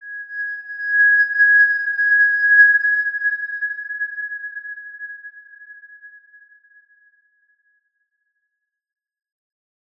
X_Windwistle-G#5-mf.wav